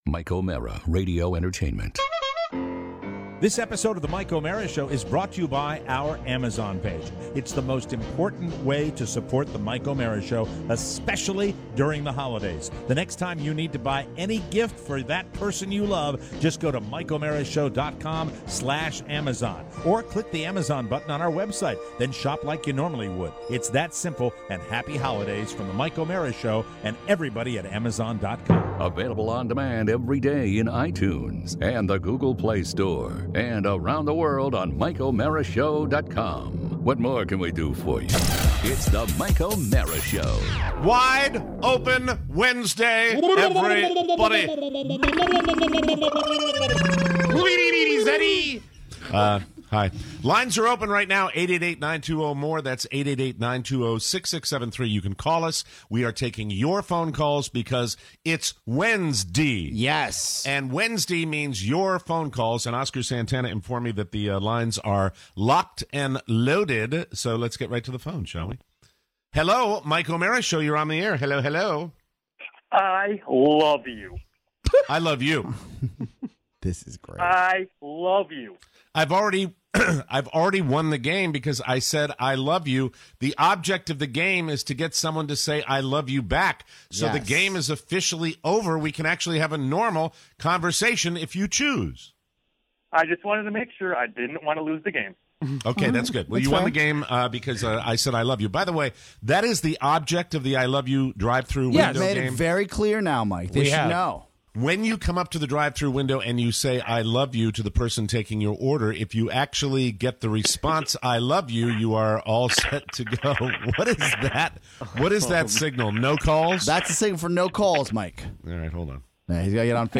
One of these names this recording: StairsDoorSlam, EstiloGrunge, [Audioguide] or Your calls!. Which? Your calls!